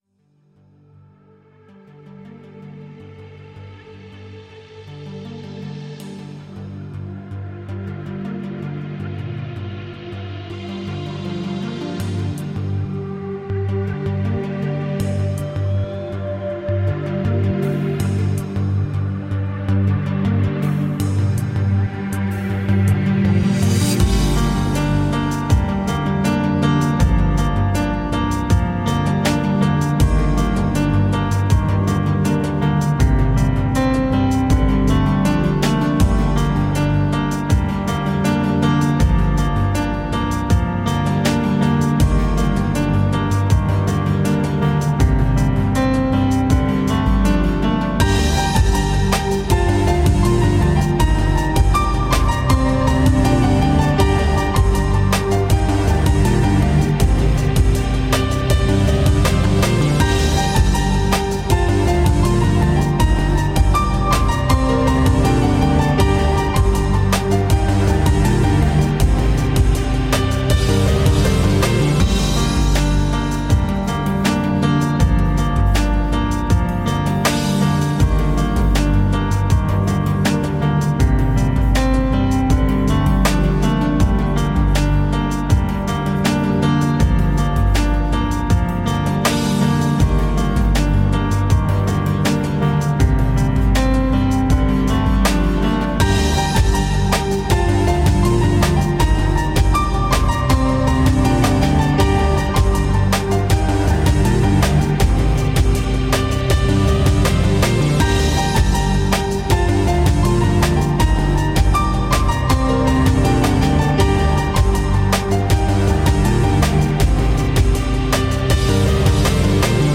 Melodic piano electro-chill-mixed.
downtempo holiday EP
Tagged as: Electronica, Techno, Chillout, Remix